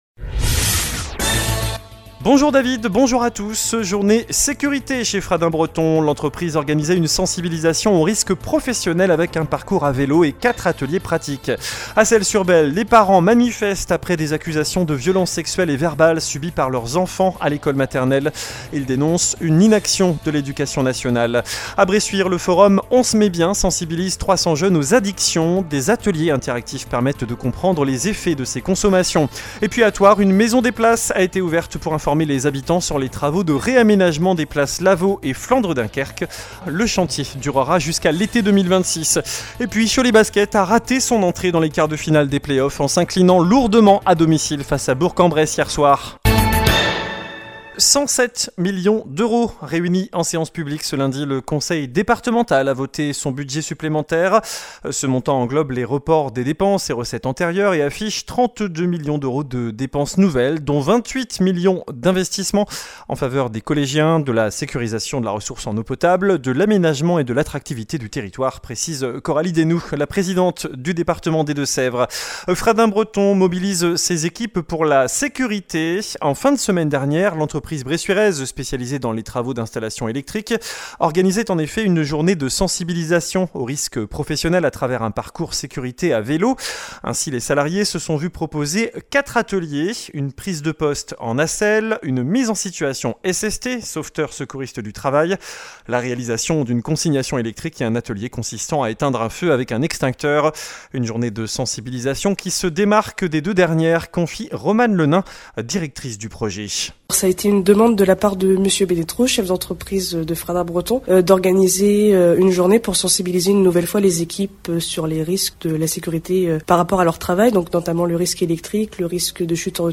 JOURNAL DU MARDI 27 MAI ( MIDI )